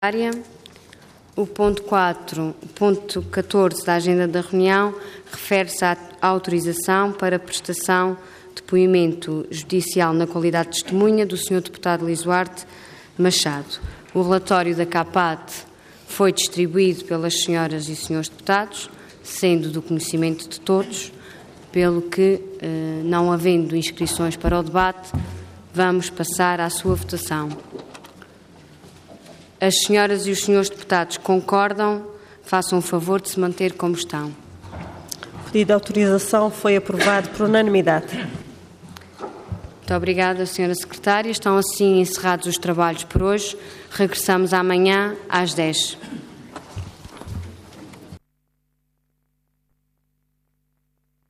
Assembleia Legislativa da Região Autónoma dos Açores
Intervenção
Presidente da Assembleia Regional